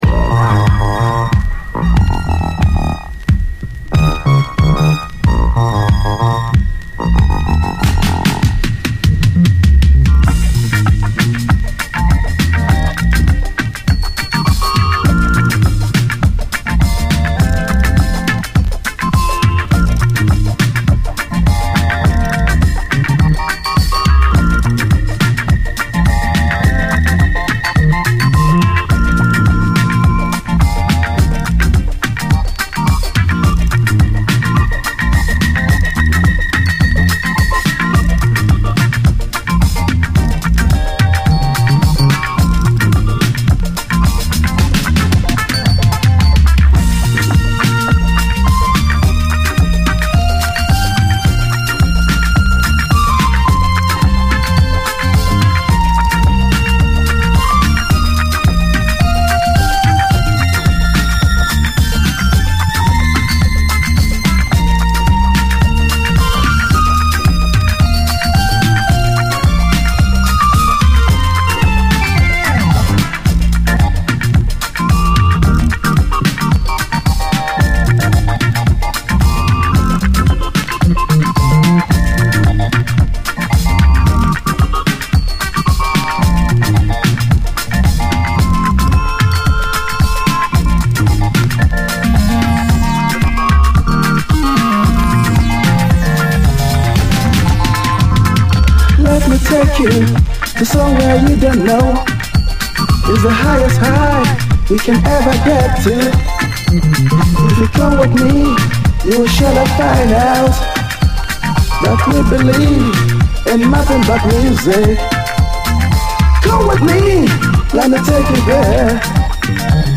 AFRO, REGGAE, WORLD
試聴ファイルはこの盤からの録音です。
一貫してメロウ＆ソウルフルなレゲエを展開！